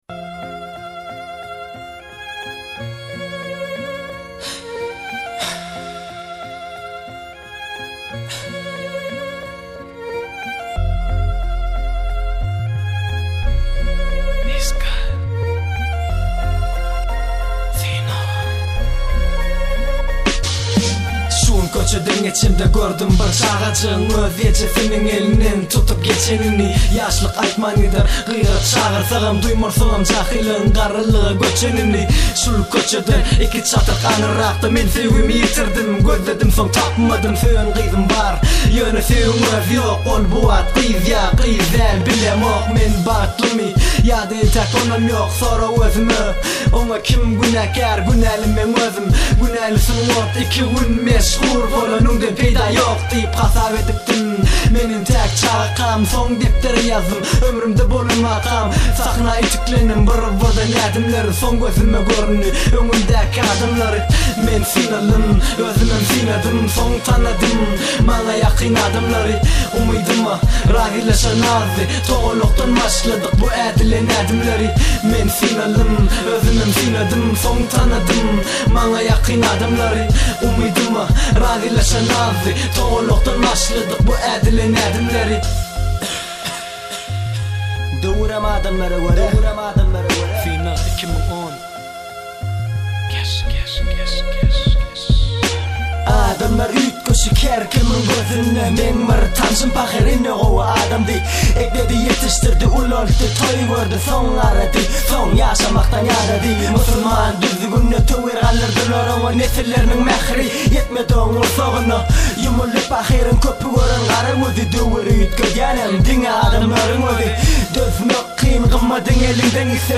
turkmen rap